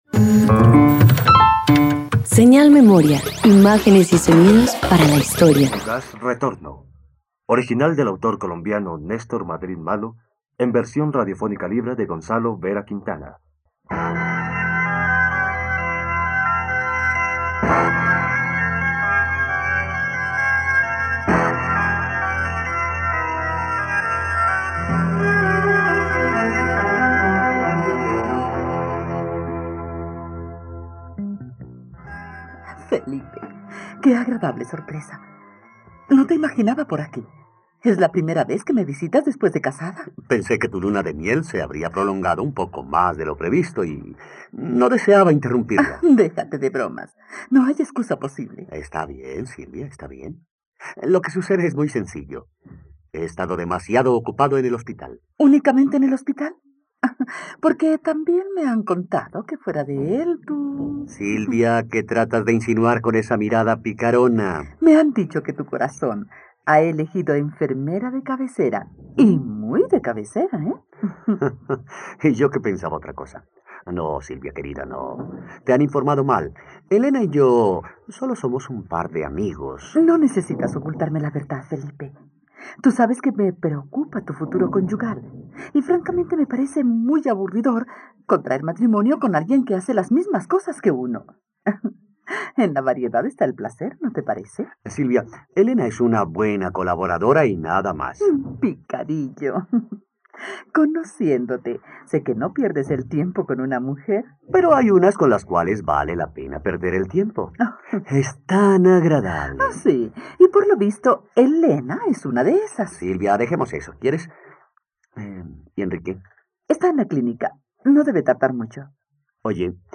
Fugaz retorno - Radioteatro dominical | RTVCPlay
..Radioteatro. Escucha la adaptación radiofónica del “fugaz retorno” de Néstor Madrid Malo por la plataforma streaming RTVCPlay.